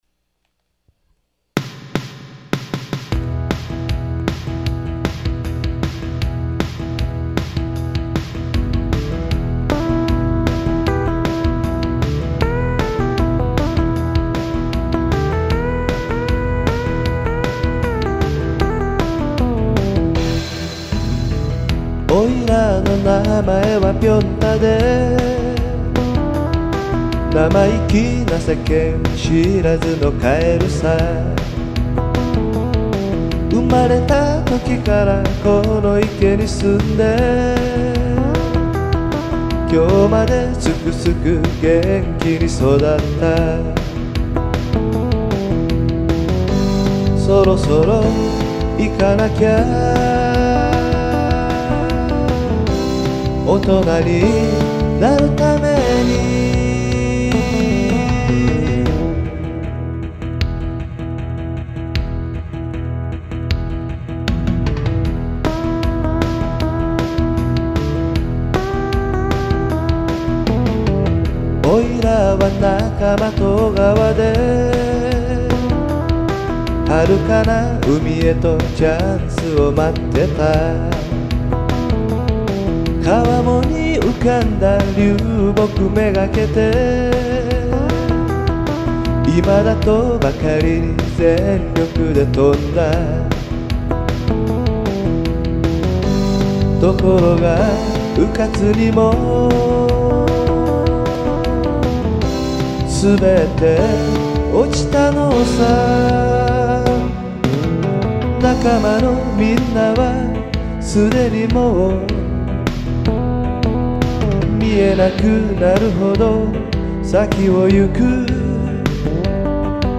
Rock'n'roll (up tempo)